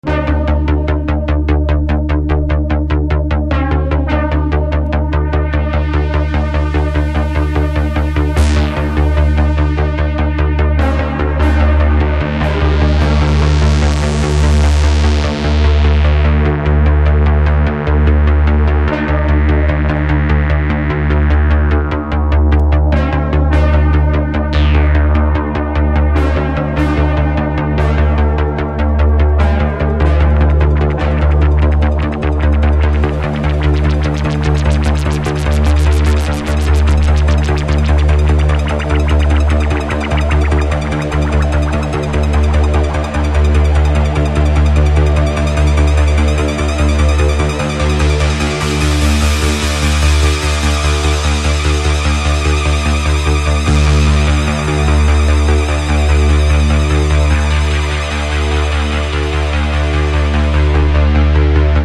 a Roland TR-707 and a Roland JX-3P
Cold weather electronics.